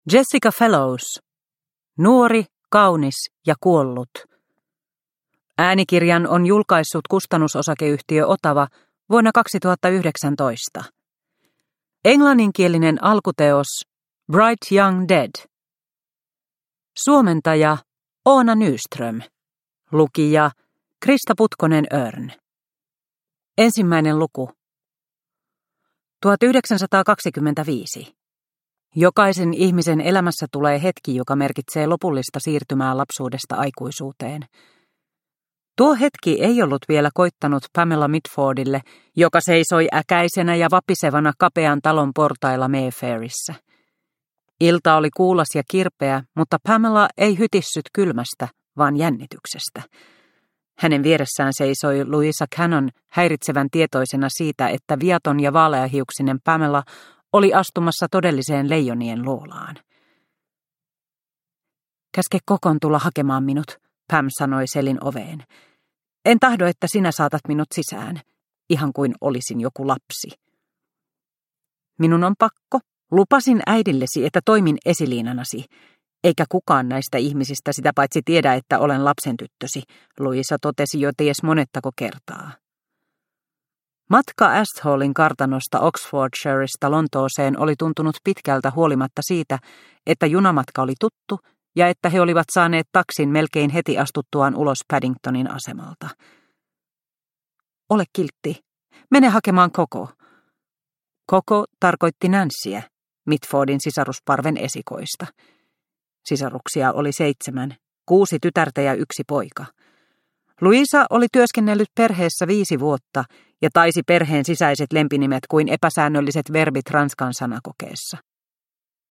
Nuori, kaunis ja kuollut – Ljudbok – Laddas ner